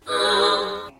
mobs_sheep.ogg